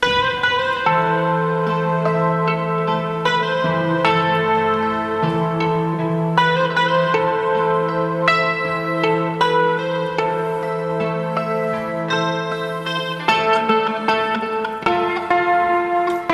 Он будет наигрывать мелодии на электрогитаре.